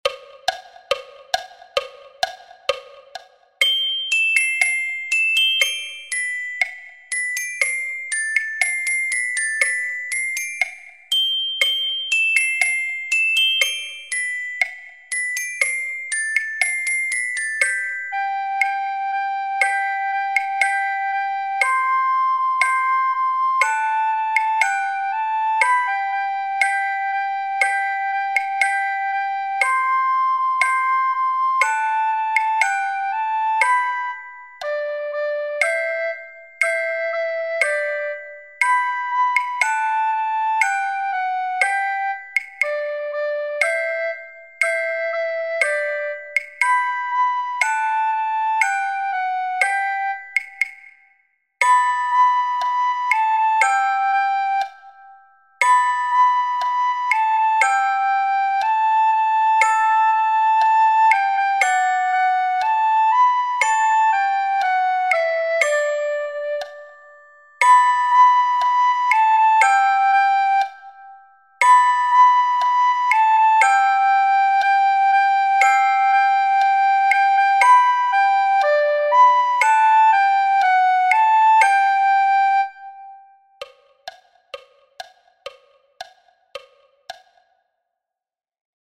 an aguinaldo-type song